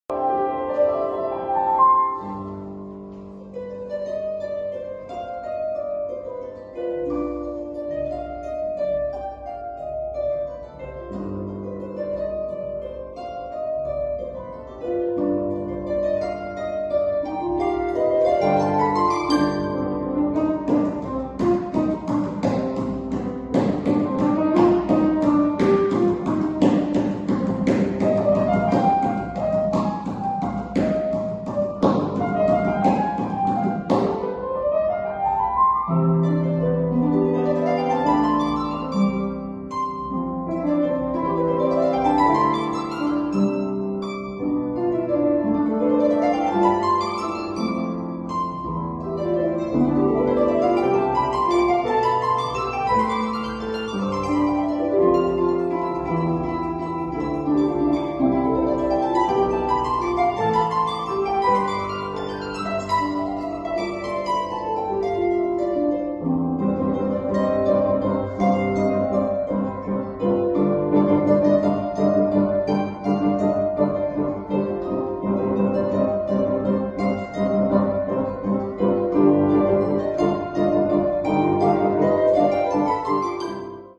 concertino para arpa y banda sinfónica.
mediante reguladores y cambios repentinos de orquestación